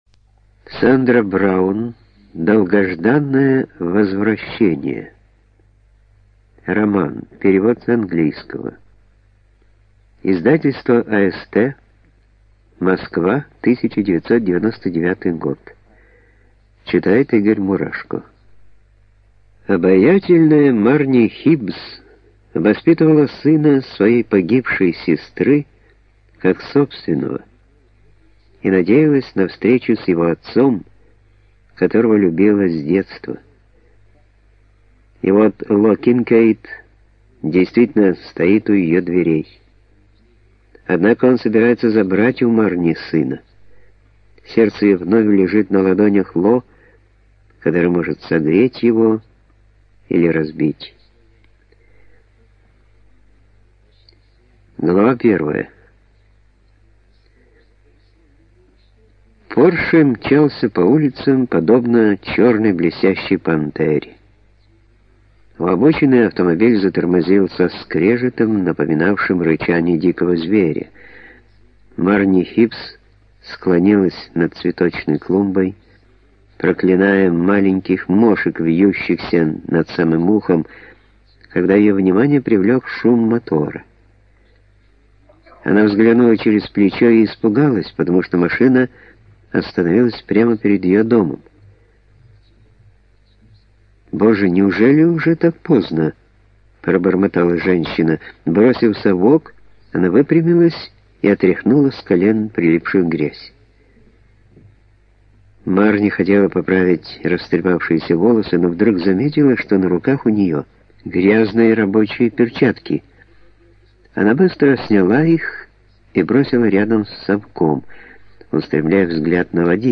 ЖанрЛюбовная проза